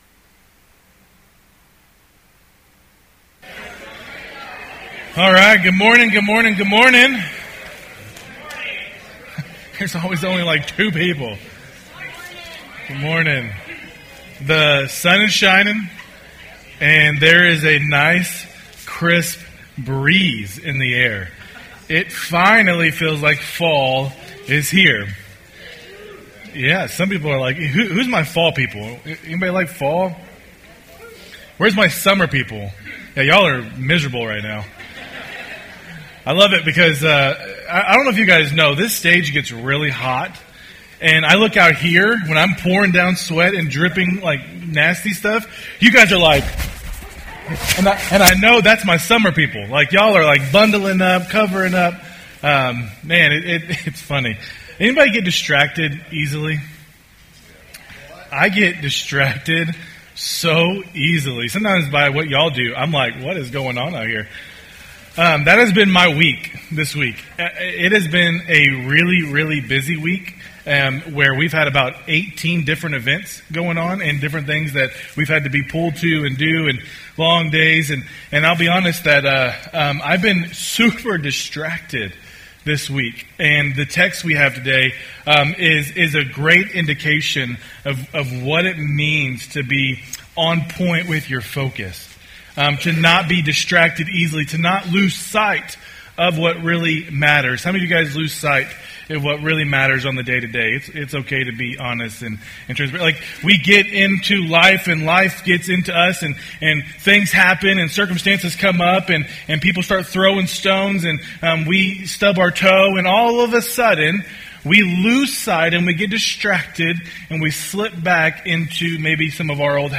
Sermons | Christian Covenant Fellowship